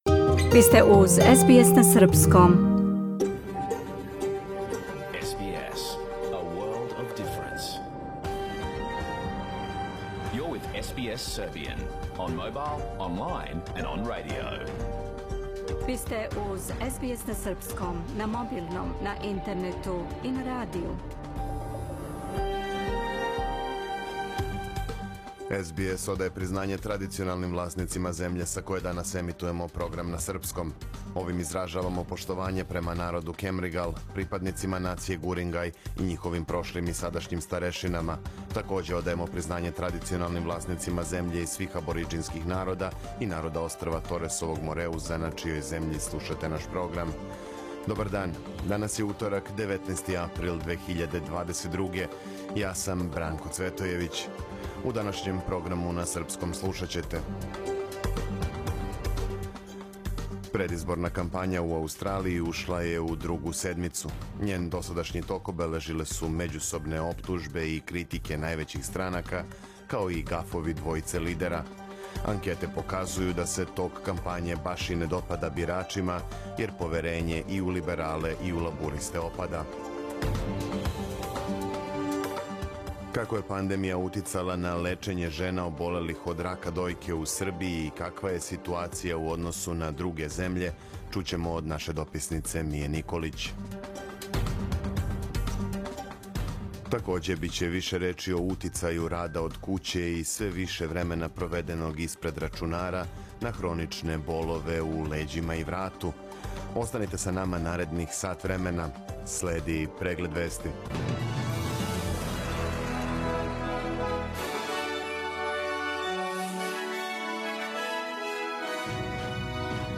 Програм емитован уживо 19. априла 2022. године
Ако сте пропустили нашу емисију, сада можете да је слушате у целини као подкаст, без реклама.